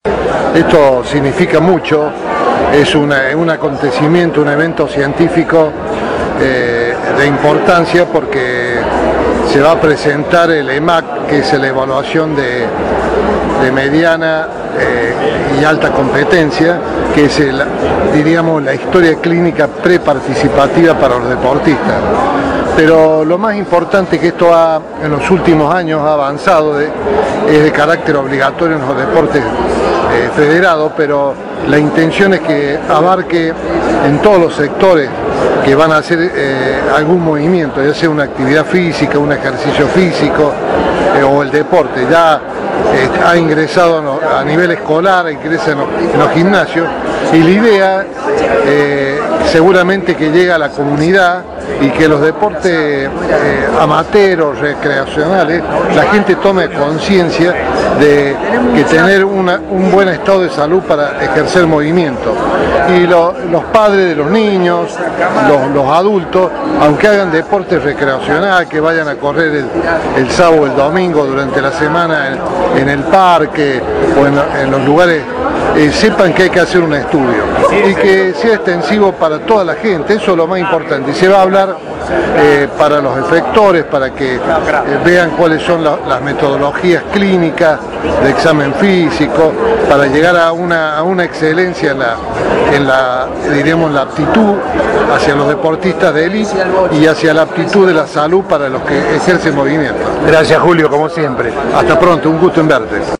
Córdoba y la modernización de la salud deportiva. Voces de los protagonistas de una Jornada Histórica en el Polo Deportivo Kempes.